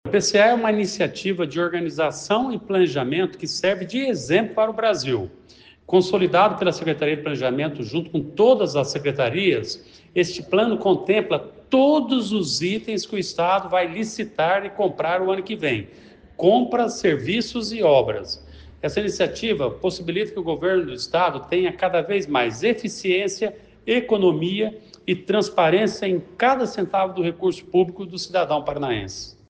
Sonora do secretário do Planejamento, Ulisses Maia, sobre o Plano de Contratações Anual de 2026